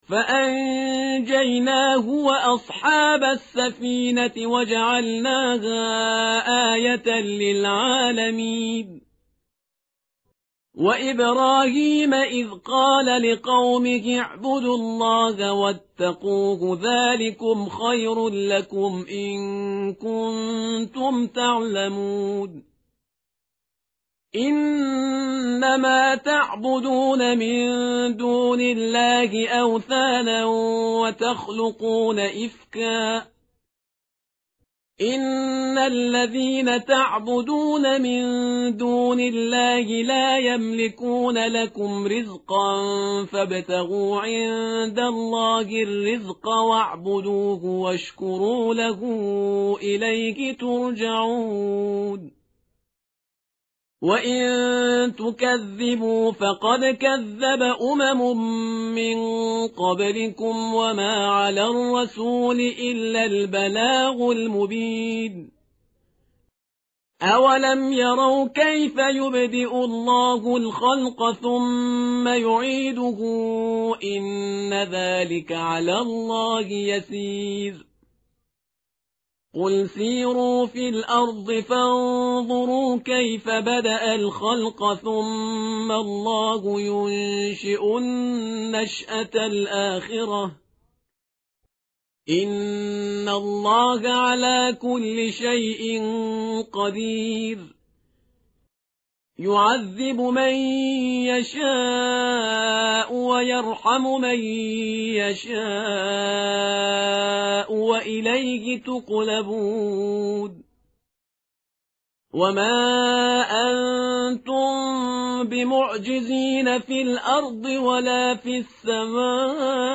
tartil_parhizgar_page_398.mp3